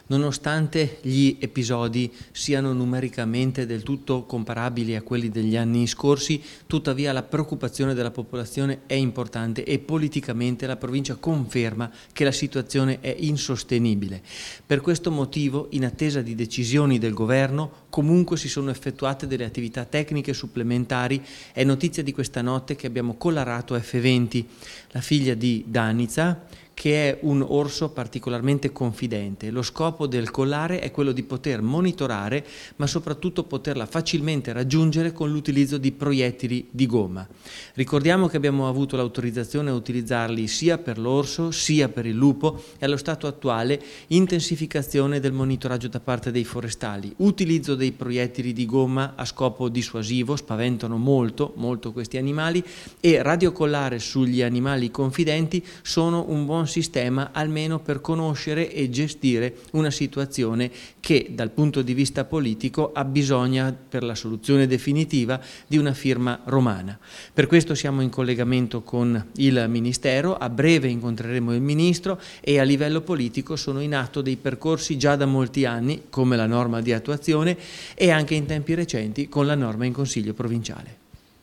L'intervista all'assessore alle foreste Michele Dallapiccola (fm) Fonte: Ufficio Stampa Ambiente Versione Stampabile Immagini Visualizza Audio DALLAPICCOLA (2) Scarica il file (File audio/mpeg 1,87 MB)